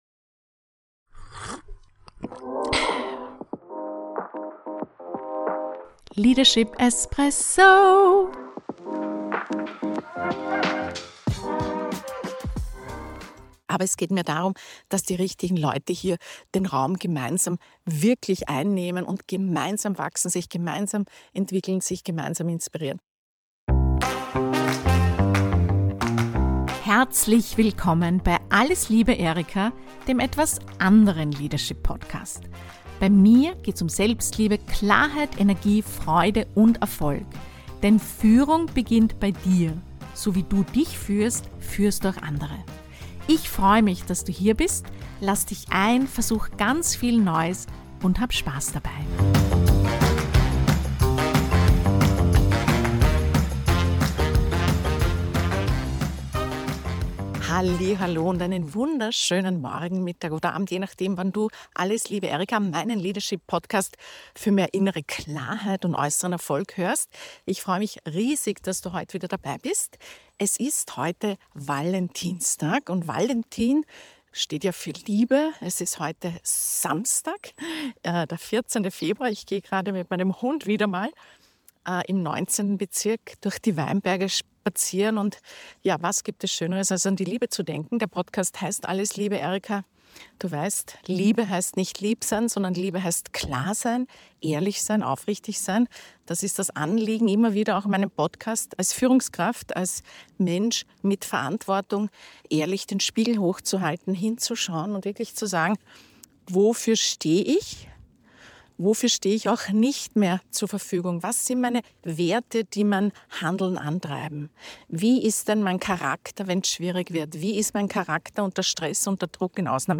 In dieser Folge, aufgenommen unterwegs am Valentinstag, geht es um die wichtigste Grundlage wirksamer Führung: dich selbst.